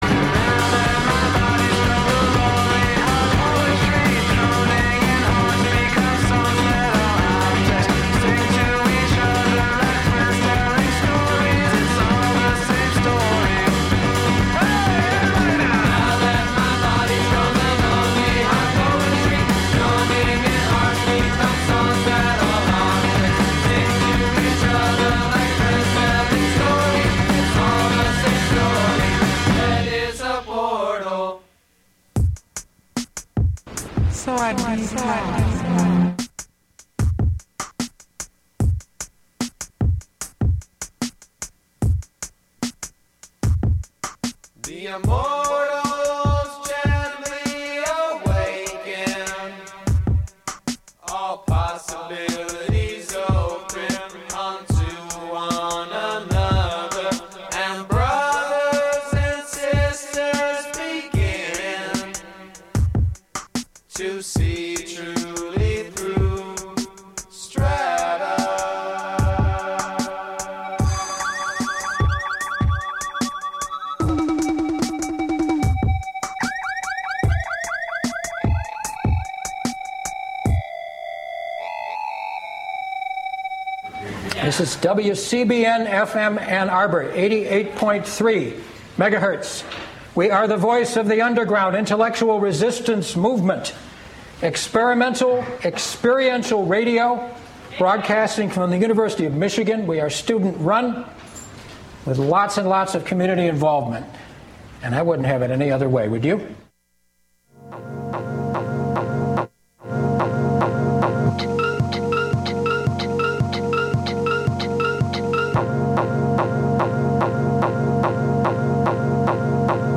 this was a special 2-hour best of 2011 mix.
the mixing isn’t that great but the tunes are.